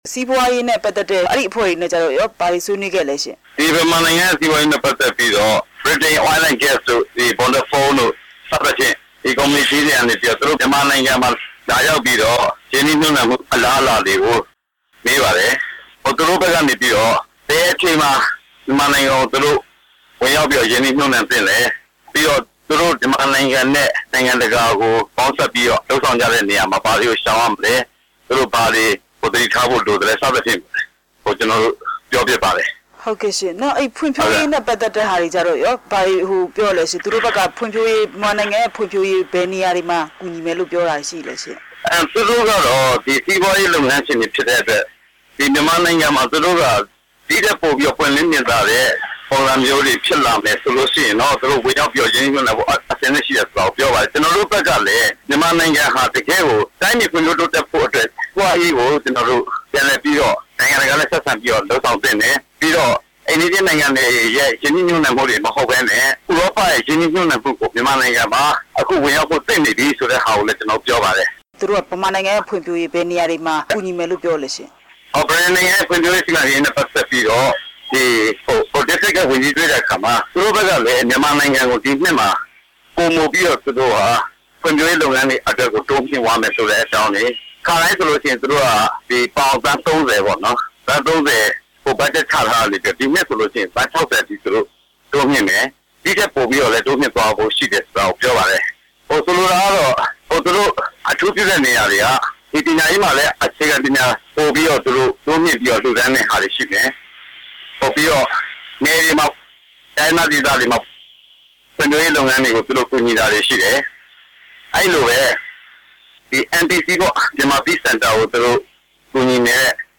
ကိုဌေးကြွယ်နဲ့ မေးမြန်းချက်